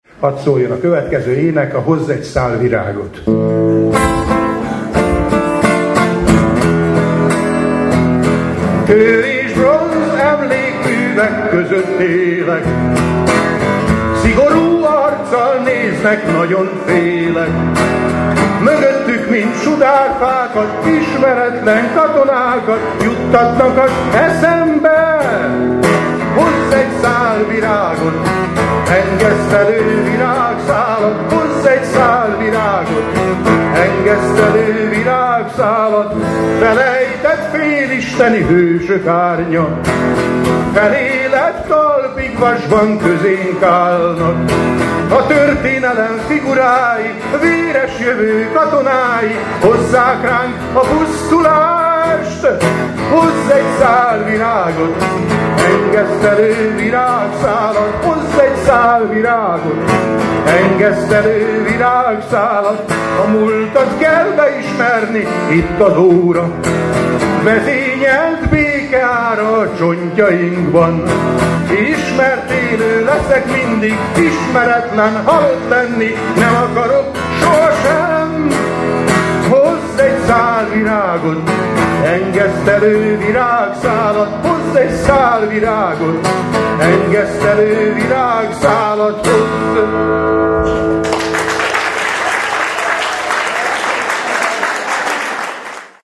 Majd következtek a dalok, amelyek eléneklésébe a közönséget is bevonta, nem kis sikerrel, ekképpen: